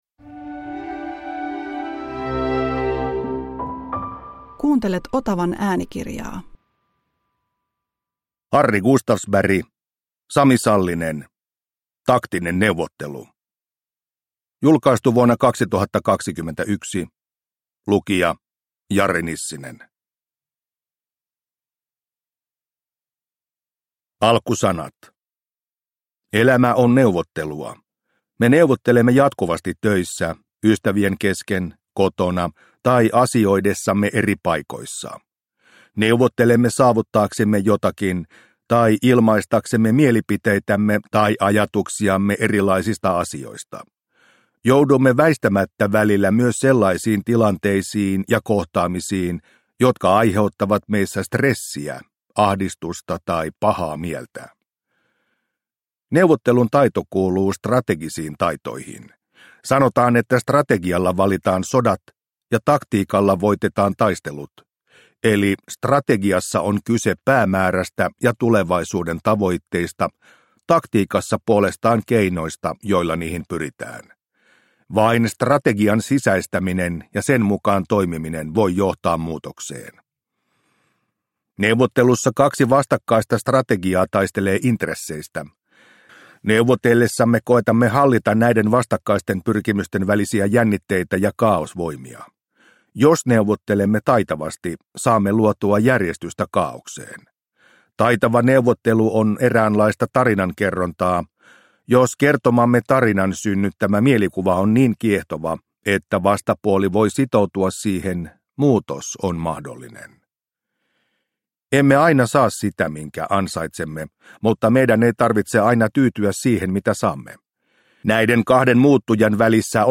Taktinen neuvottelu – Ljudbok – Laddas ner
Produkttyp: Digitala böcker